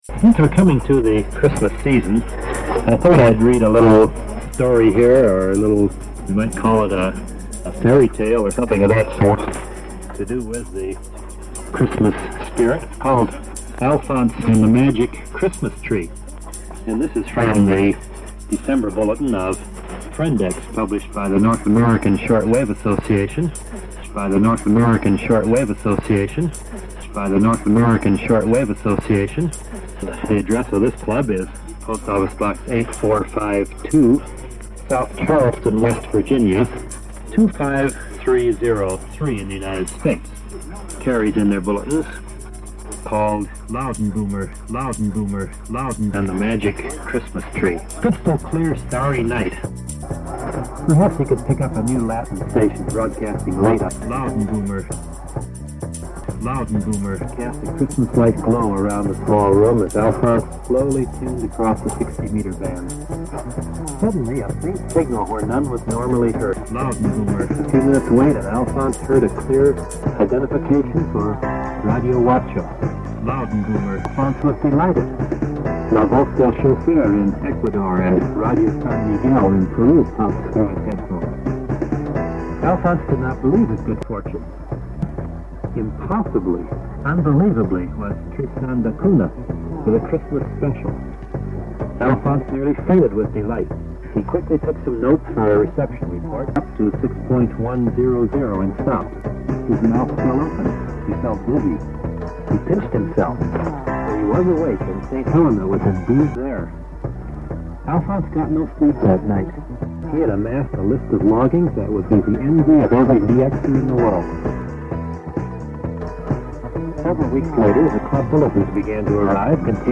Part of the Shortwave Transmissions project, documenting and reimagining the sounds of shortwave radio